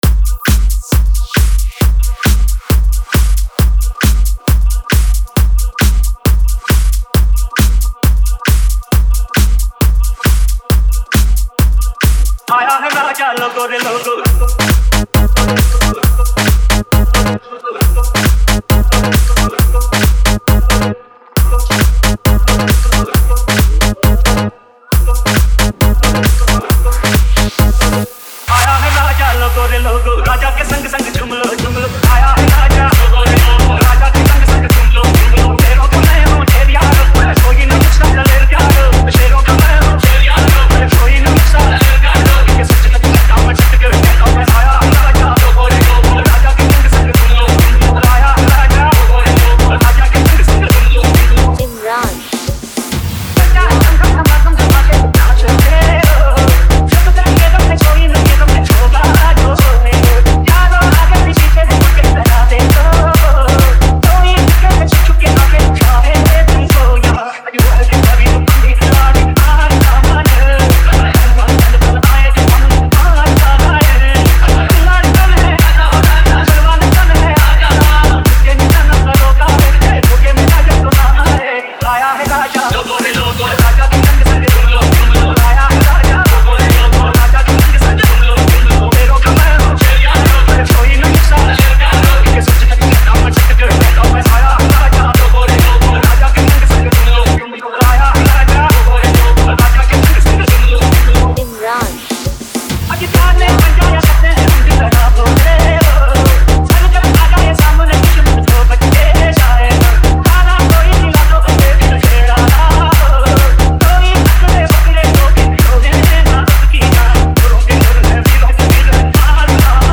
Marathi Dj Single 2025